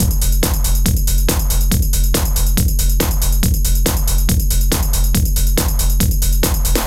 NRG 4 On The Floor 030.wav